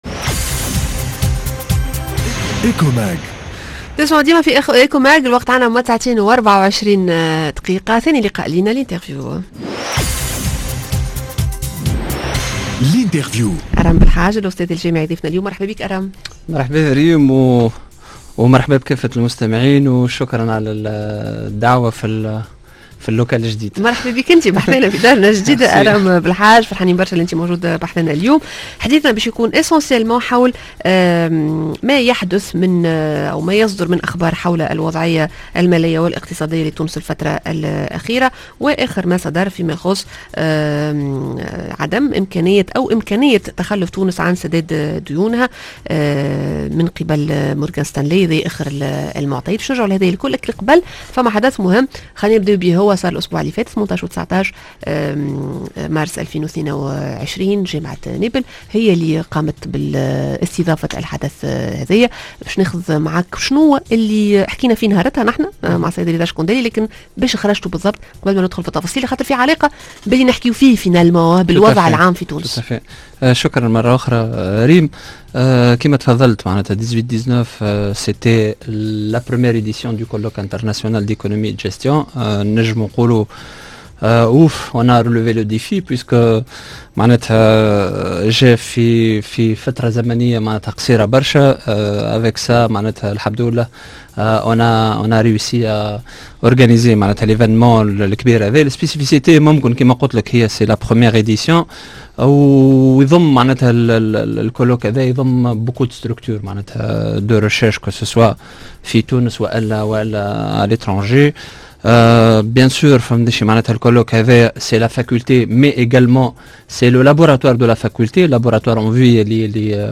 L'interview: تونس تنجّم تتخلّف على سداد ديونها؟